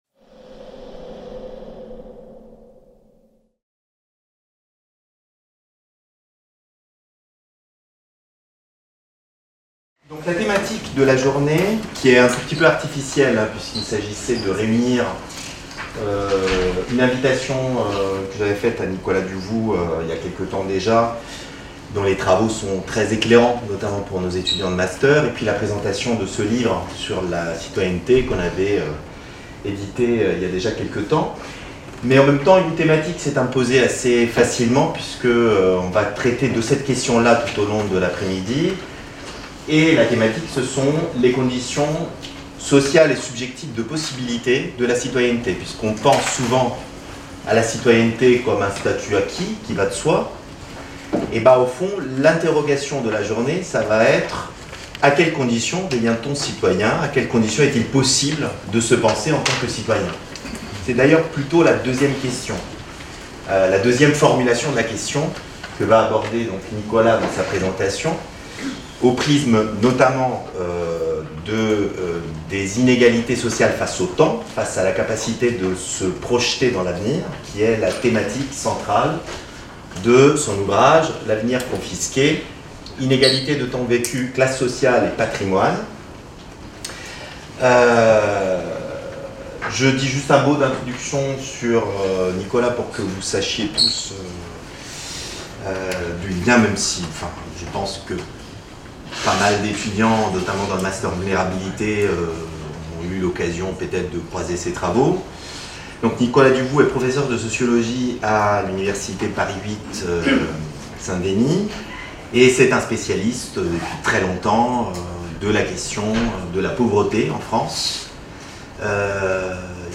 Séminaire des invités Master Sociologie